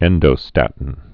(ĕndō-stătn)